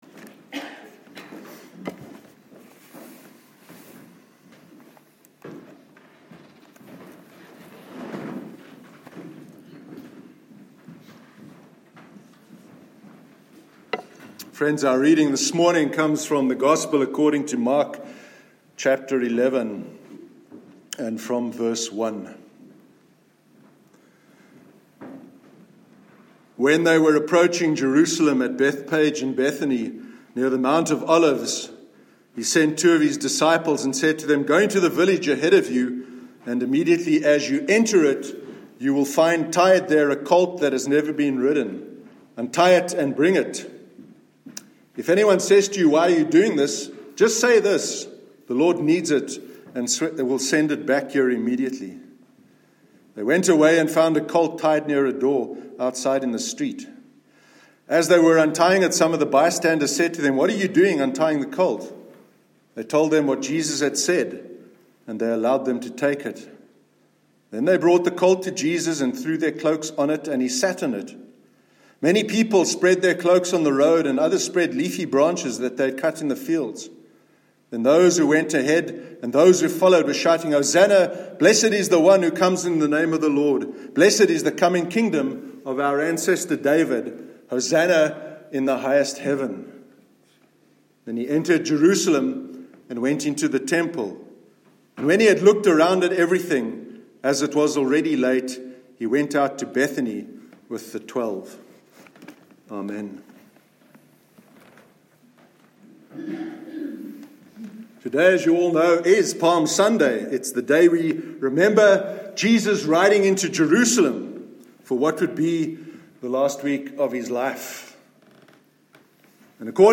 Palm Sunday Service- 25th March 2018